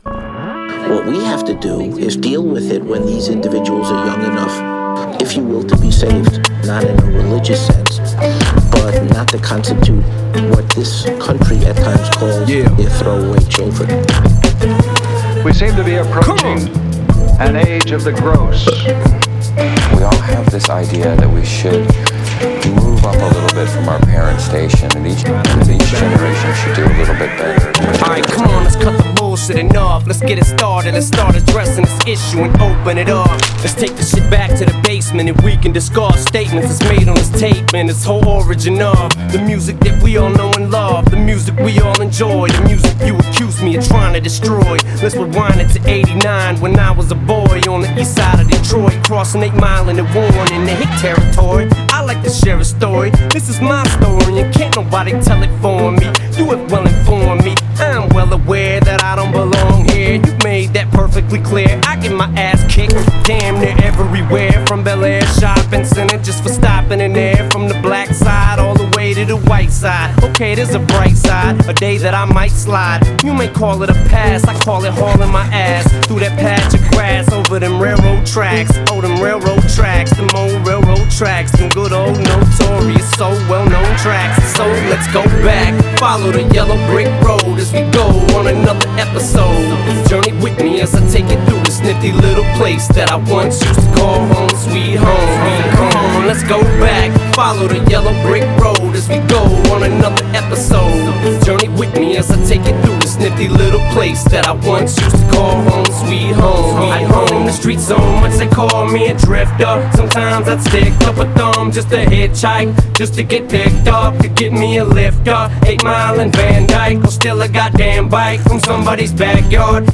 Жанр: Rap